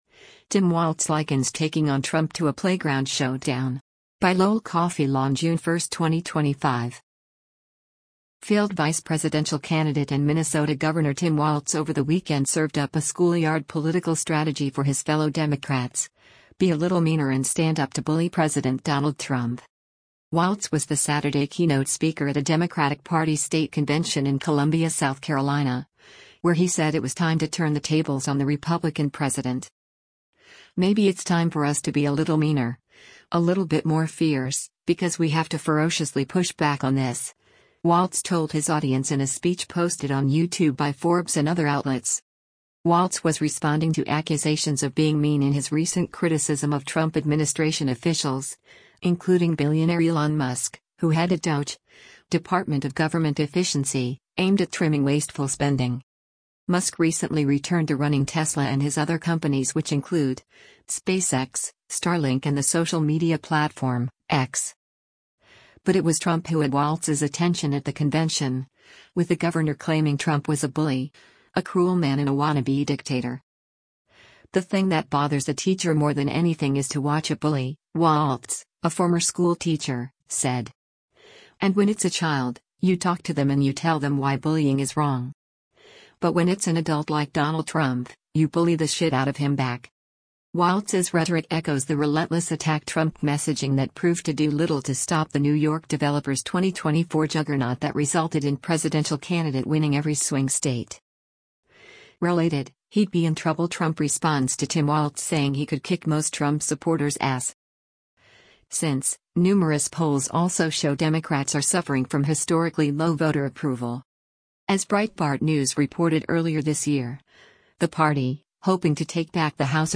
Walz was the Saturday keynote speaker at a Democratic Party state convention in Columbia, South Carolina, where he said it was time to turn the tables on the Republican president.